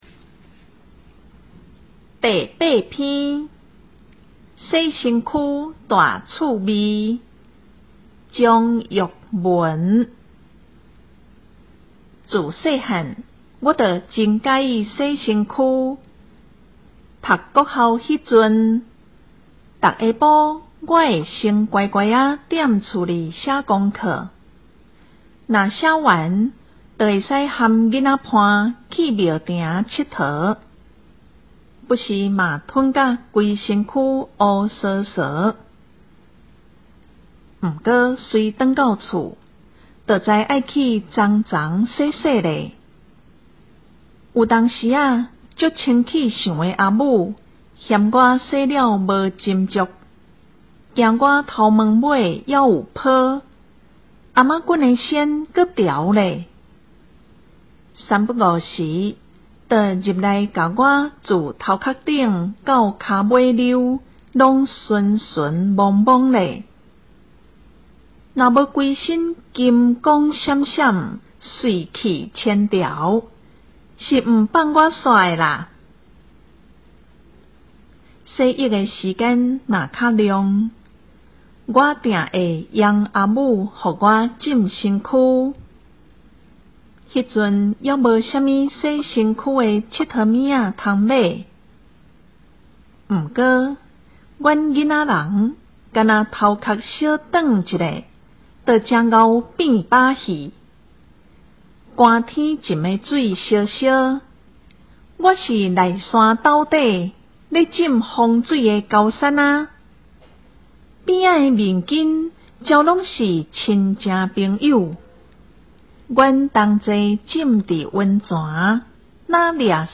114學年度四、五年級臺灣台語朗讀稿